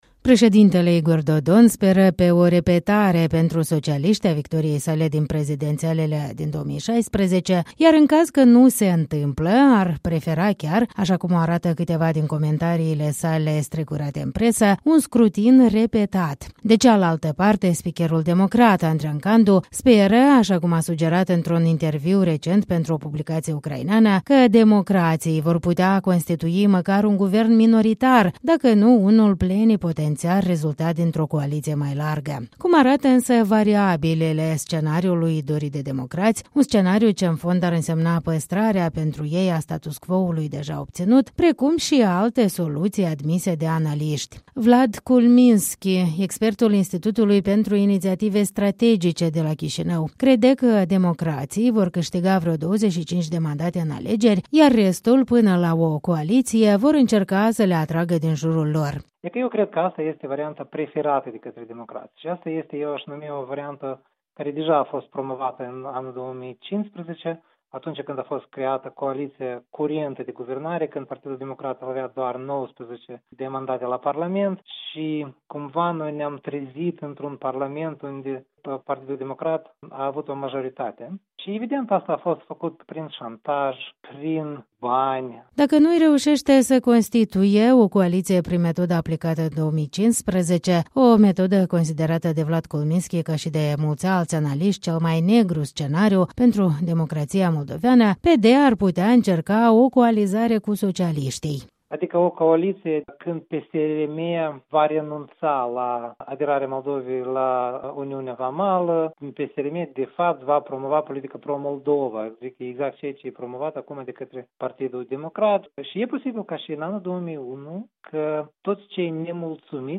Câțiva analiști despre scenariile post-electorale.